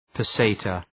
Προφορά
{pə’seıtə}